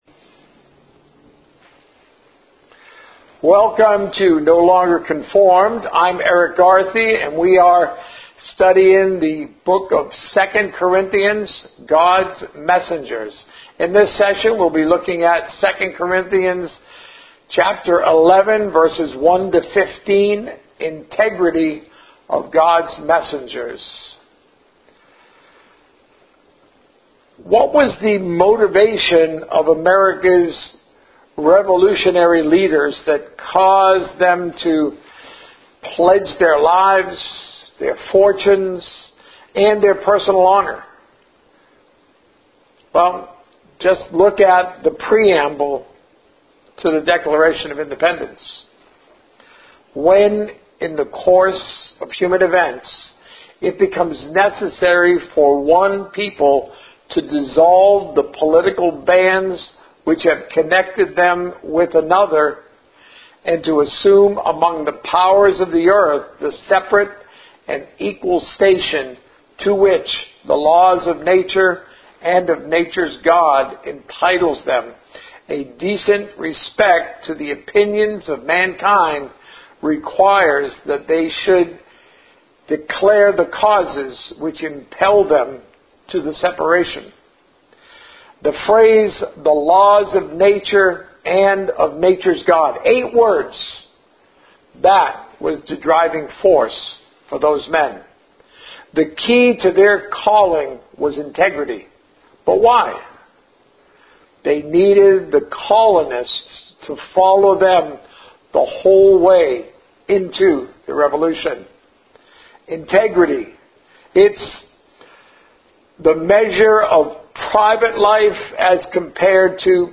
Sermons - No Longer Conformed